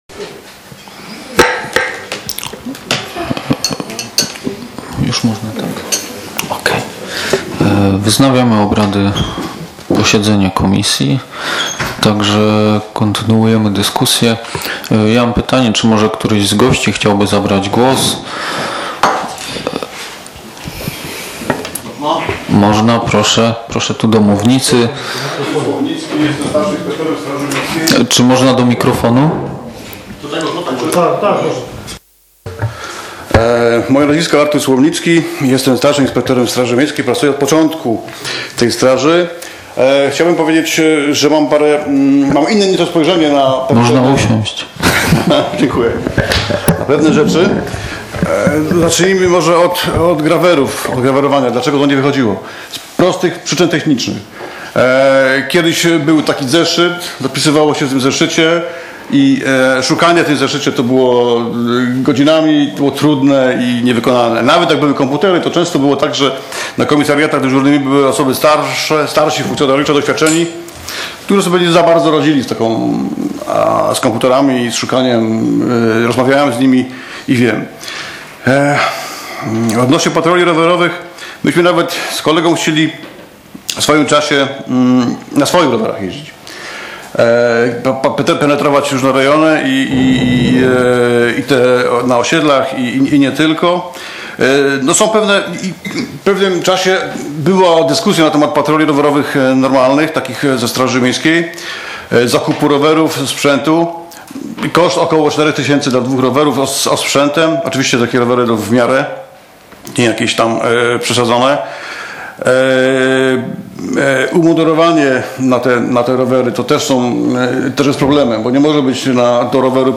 z posiedzenia Komisji Rolnictwa, Leśnictwa, Ochrony Środowiska i Ładu Publicznego w dniu 10.02.2015 r.